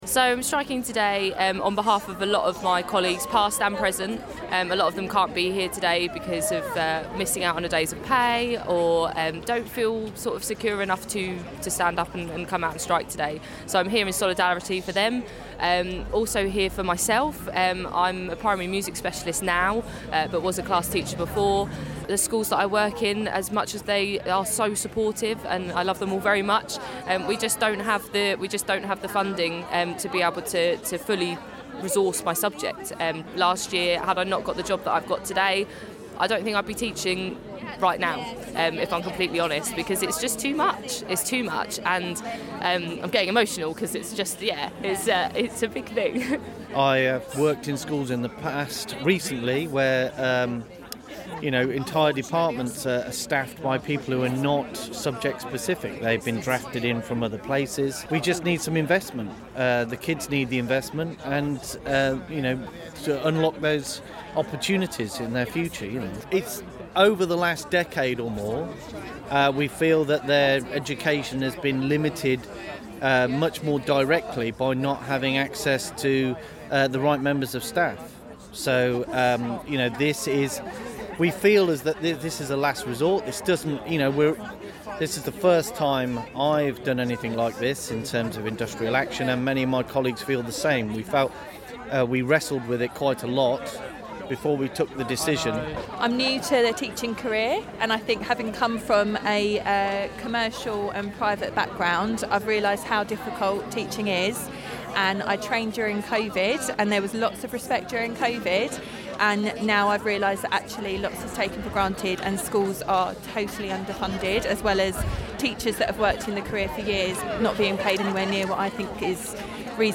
LISTEN: Teachers from NEU join a march and rally at Brenchley Gardens in Maidstone - 01/02/2023